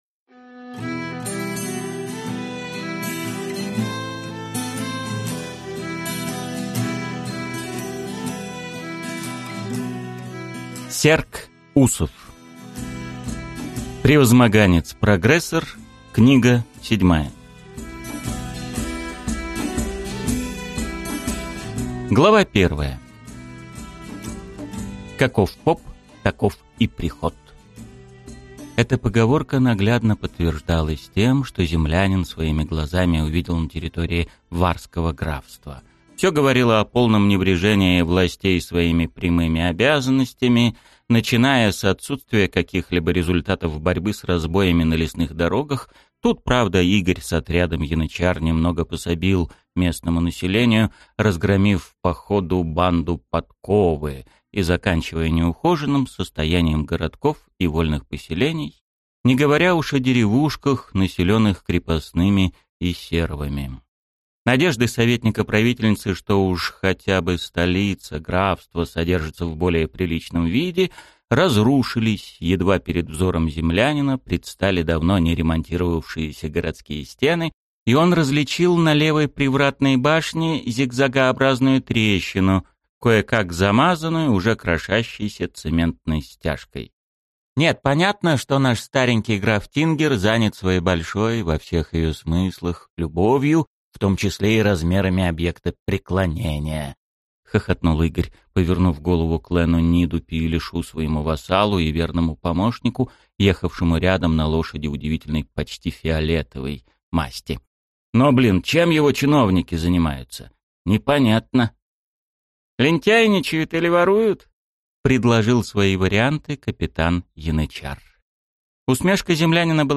Аудиокнига Превозмоганец-прогрессор. Книга 7 | Библиотека аудиокниг